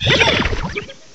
sovereignx/sound/direct_sound_samples/cries/mareanie.aif at master
mareanie.aif